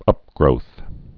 (ŭpgrōth)